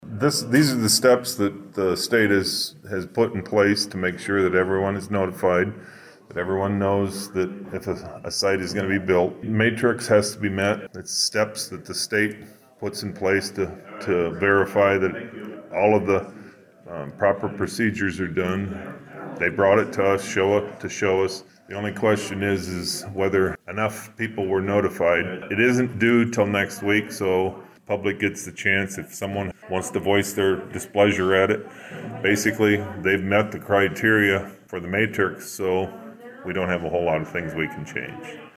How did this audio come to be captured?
Dakota City, IA -At Monday’s Humboldt County Board of Supervisors Meeting there was a public hearing and discussion about the construction of a hog site in Humboldt County.